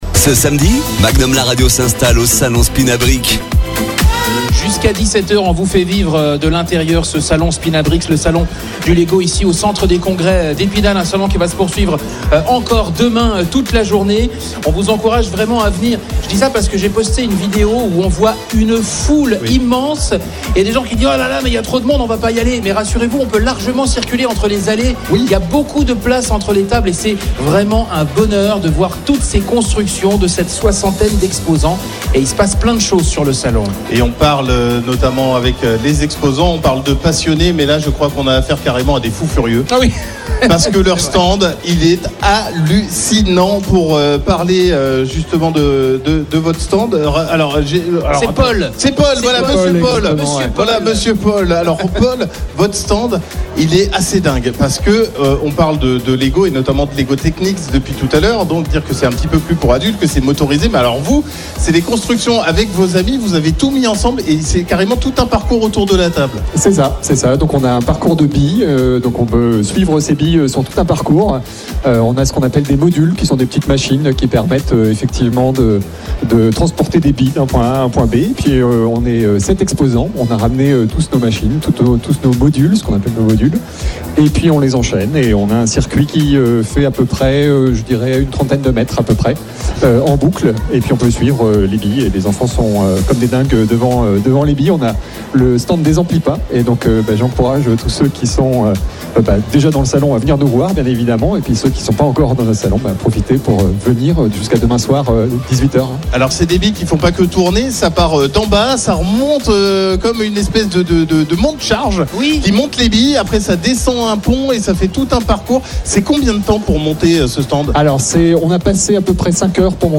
Emission spéciale en direct du salon SPINABRICKS au centre des congrès d'Épinal
Interview